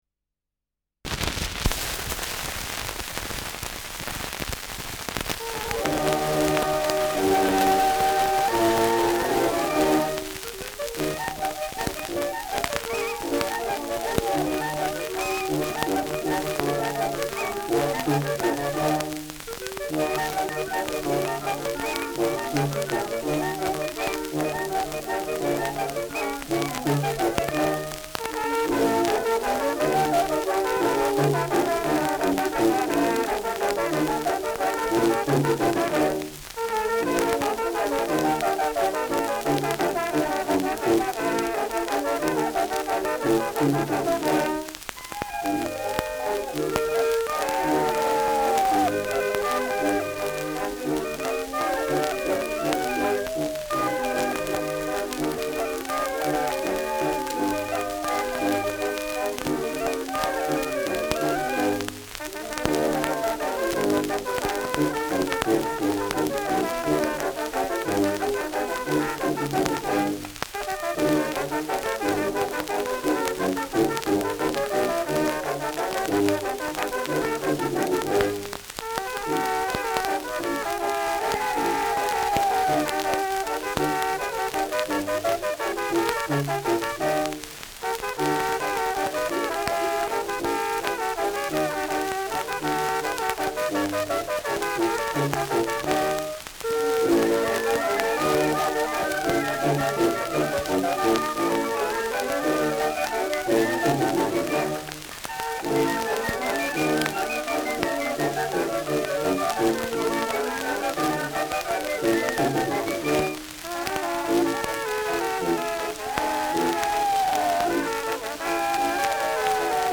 Schellackplatte
Starkes Grundrauschen : Durchgehend stärkeres Knacken